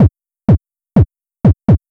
KICK012_DISCO_125_X_SC3.wav
1 channel